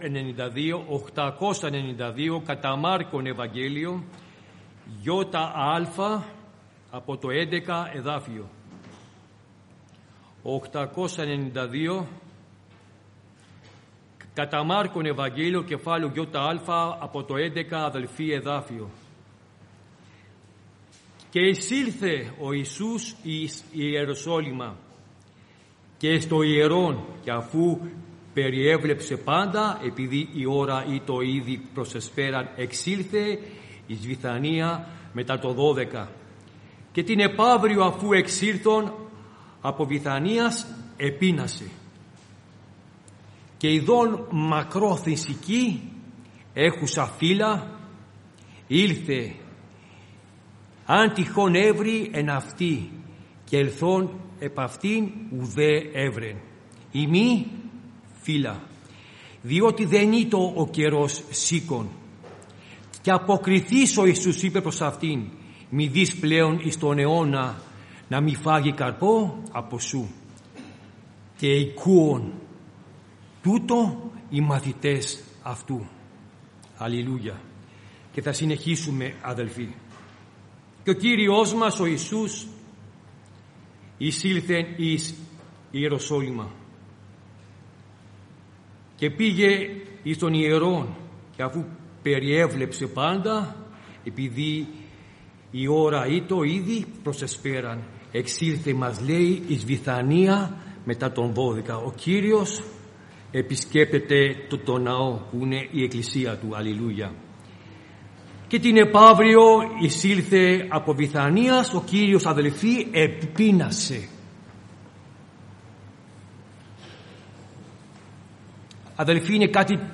Κηρύγματα 2024 Ημερομηνία